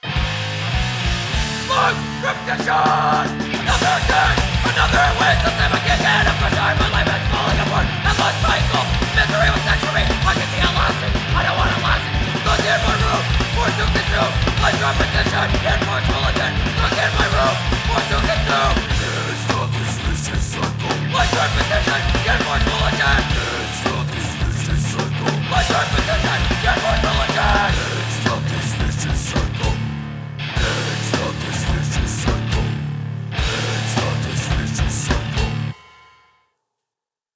Loud and heavy music
Punk Rock Music